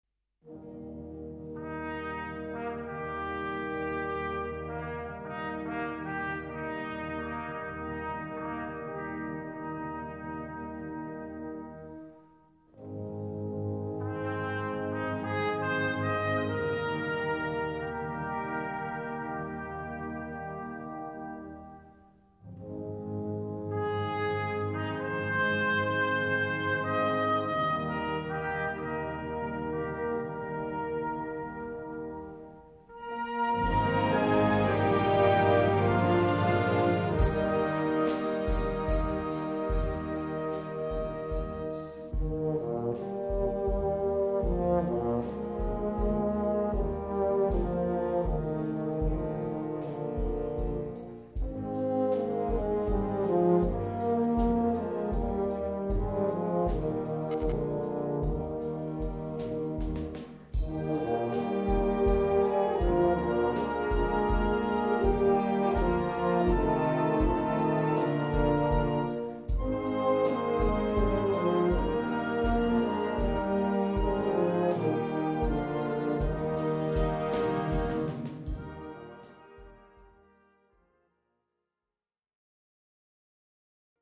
Gattung: Solostück
Besetzung: Blasorchester